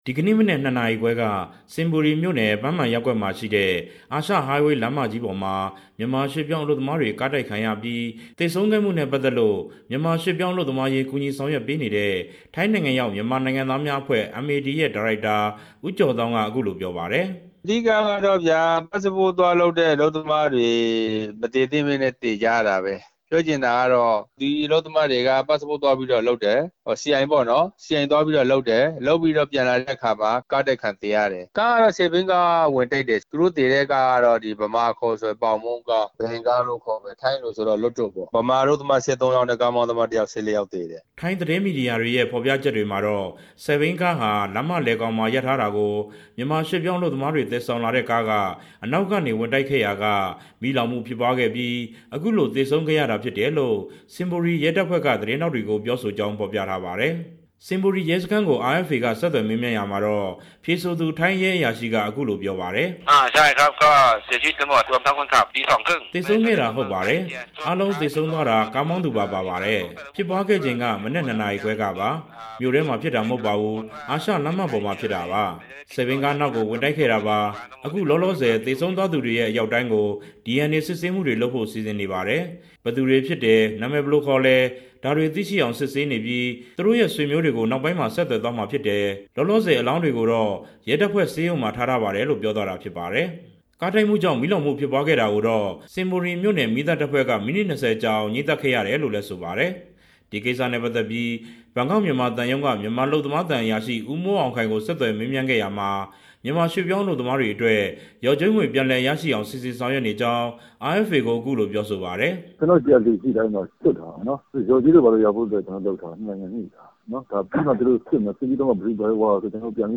မြန်မာ ၁၃ ဦး သေဆုံးရတဲ့ ထိုင်းနိုင်ငံက ယာဉ်တိုက်မှု သတင်းပေးပို့ချက်